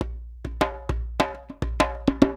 100 JEMBE1.wav